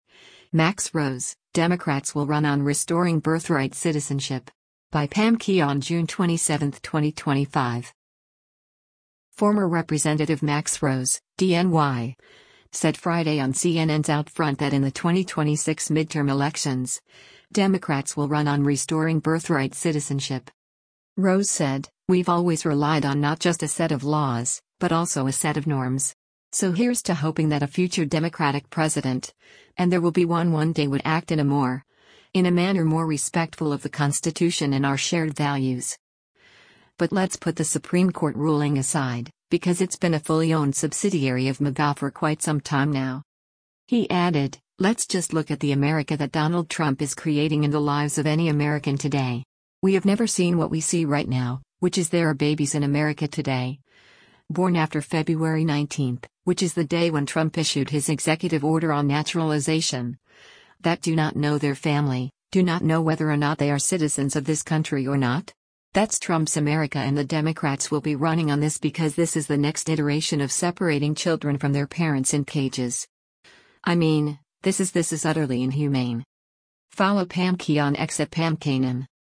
Former Rep. Max Rose (D-NY) said Friday on CNN’s “OutFront” that in the 2026 midterm elections, Democrats will run on restoring birthright Citizenship.